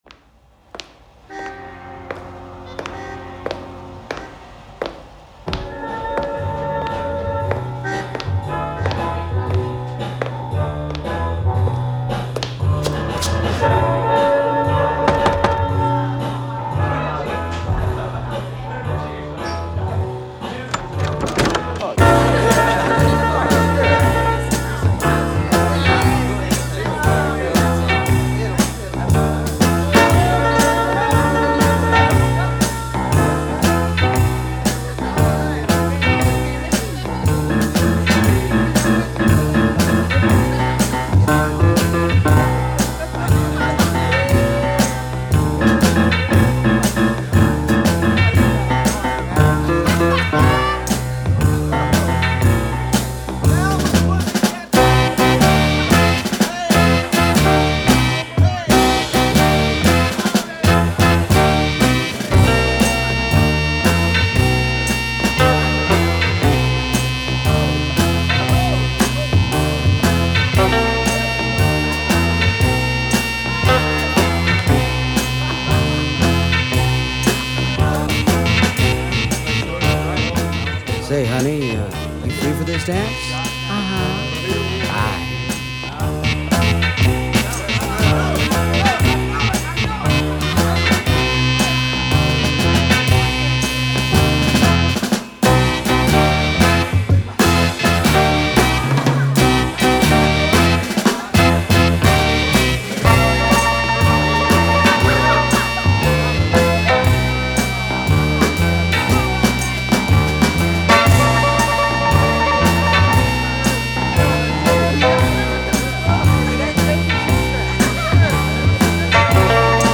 Cut live in the studio
grinding their way through a mod/soul/r’n’b groover